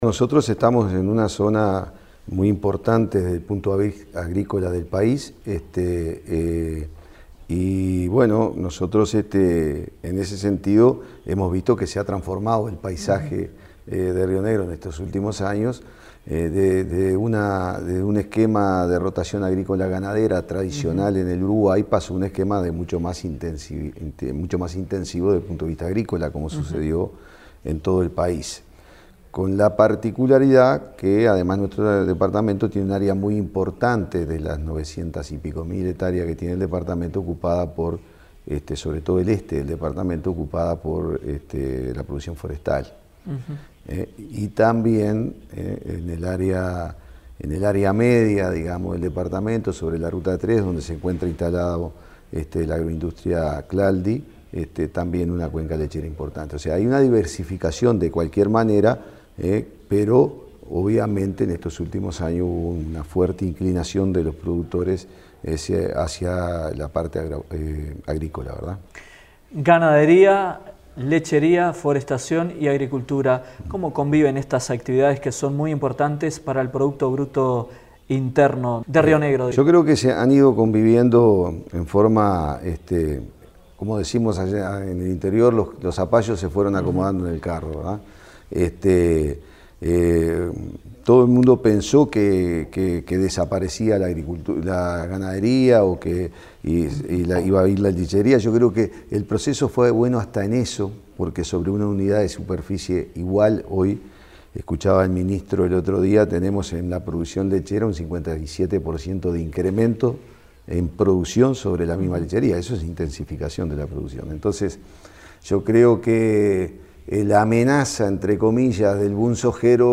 En la búsqueda de dar respuesta a los inversores que demandan mejor infraestructura, el intendente electo por ese departamento, Oscar Terzaghi, en diálogo con  Dinámica Rural, dijo que solicitará  al gobierno central una mayor partida de dinero, para poder mantener los más de 2.600 km de caminería rural, "pieza clave para el desarrollo productivo y económico del departamento";.